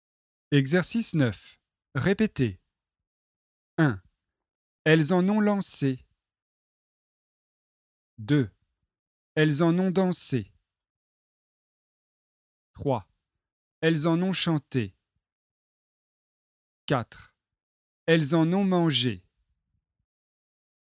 Exercice 9 : répétez (AN - ON - AN)